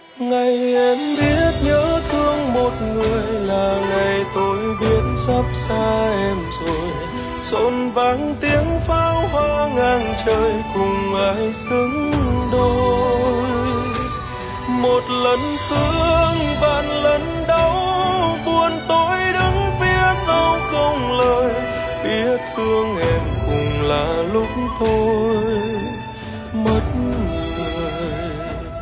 Nhạc Trẻ - Nhạc Lofi.